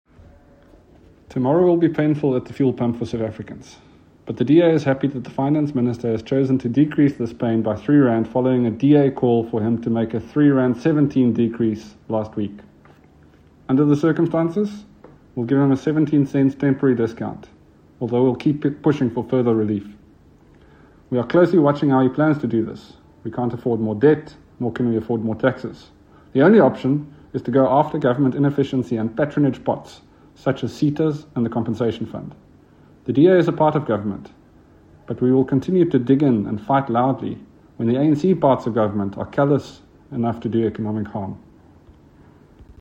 Soundbite by Dr Mark Burke MP.